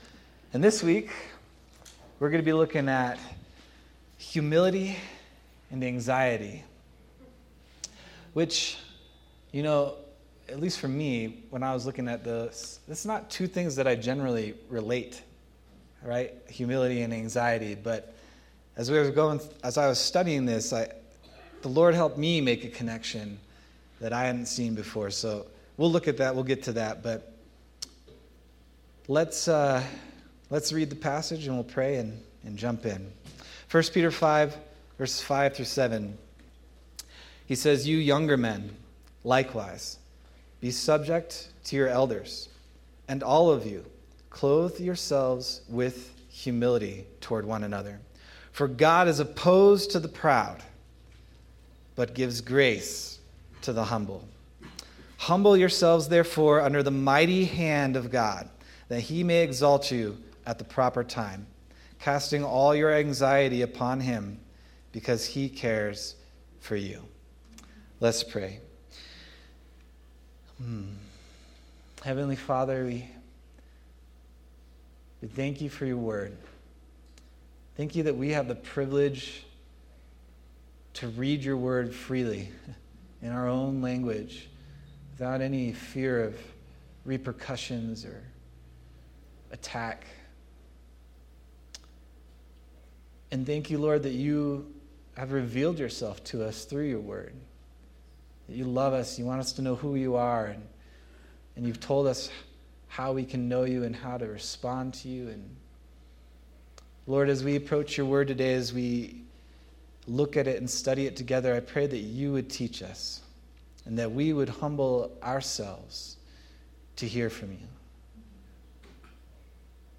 August 3rd, 2025 Sermon